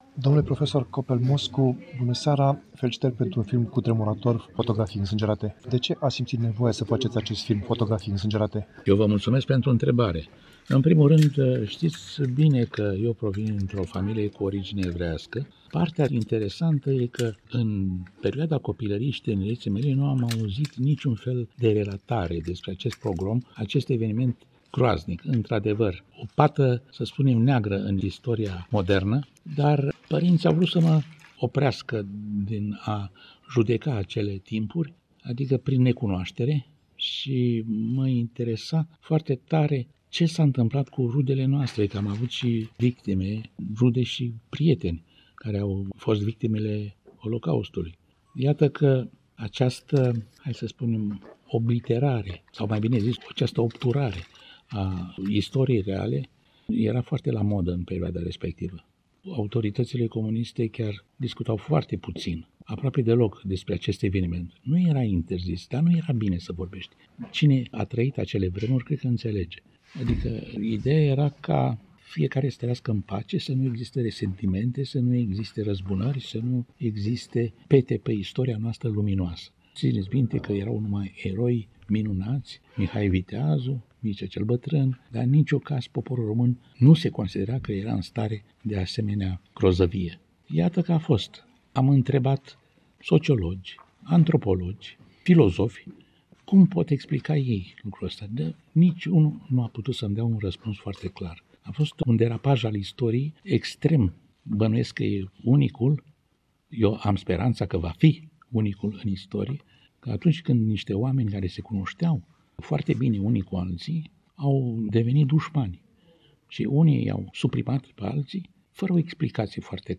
Am stat de vorbă după premieră